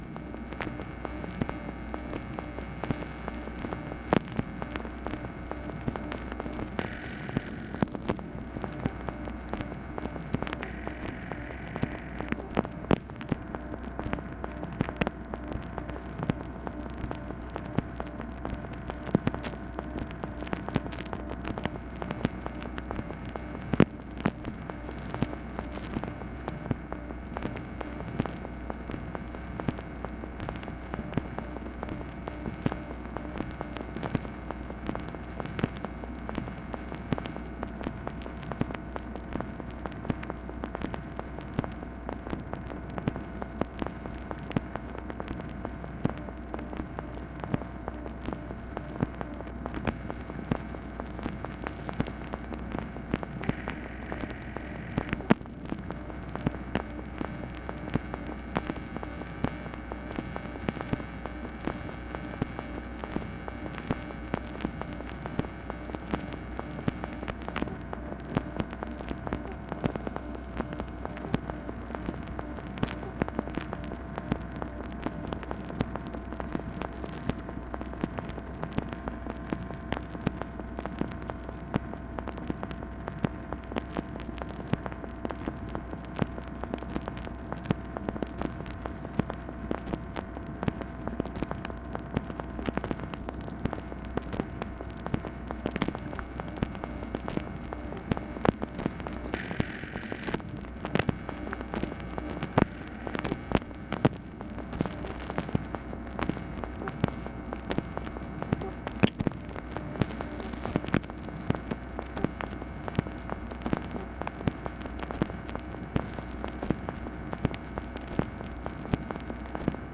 MACHINE NOISE, December 30, 1968
Secret White House Tapes